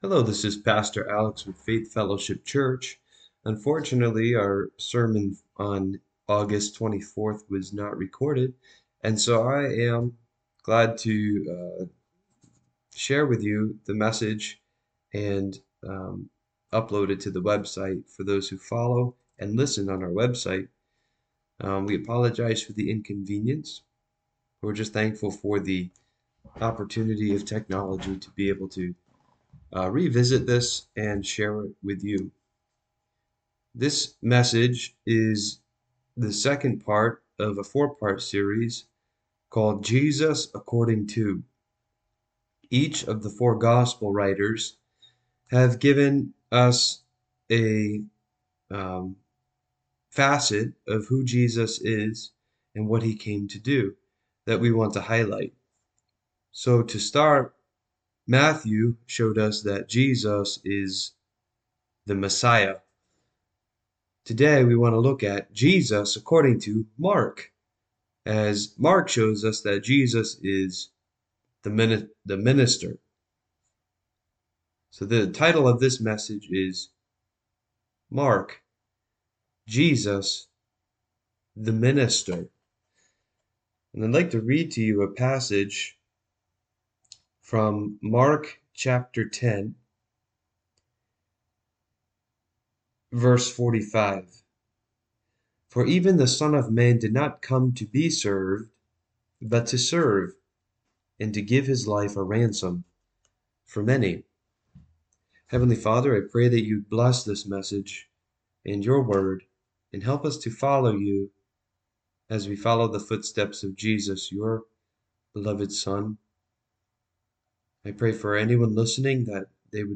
Mark 10:45 Service Type: Sunday Morning Worship What if greatness isn’t found in being served—but in pouring your life out for others?